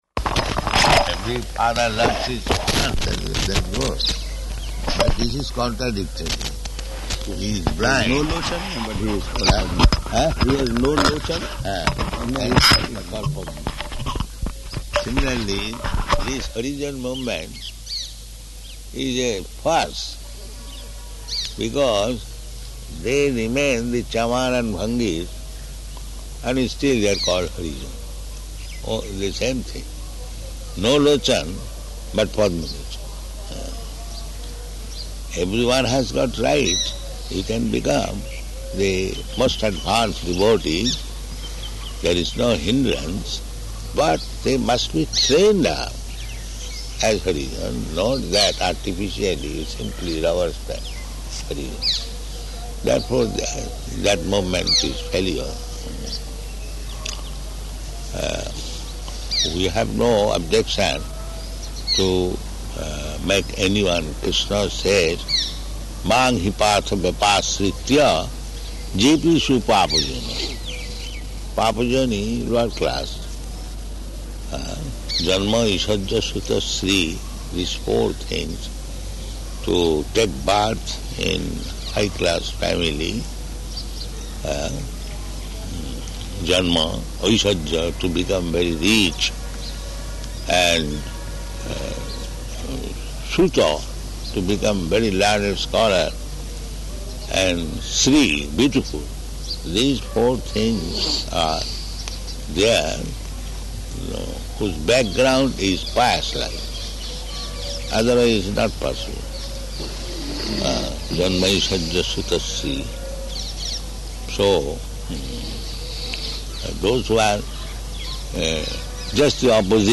Garden Conversation
Garden Conversation --:-- --:-- Type: Conversation Dated: October 9th 1976 Location: Aligarh Audio file: 761009G1.ALI.mp3 Prabhupāda: Every father loves his son.